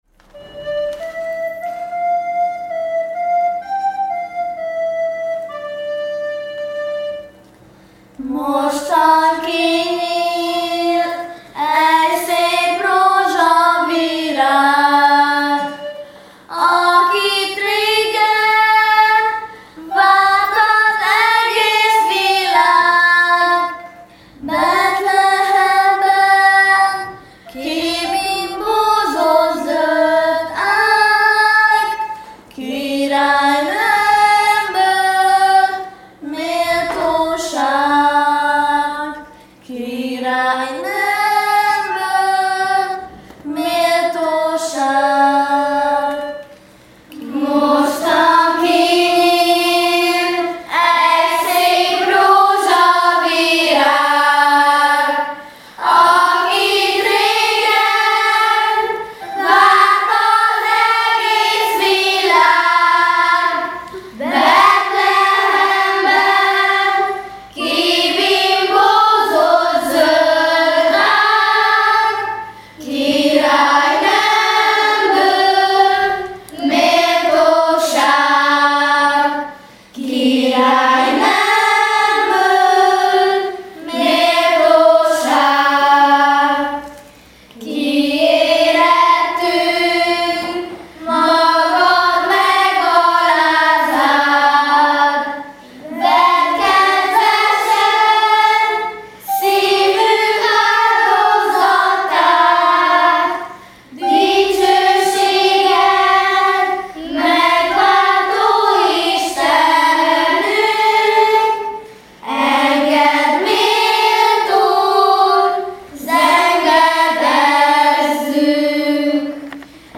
Ajándékul fogadjanak el néhány karácsonyi dalt iskolánk gyermekkórusa előadásában.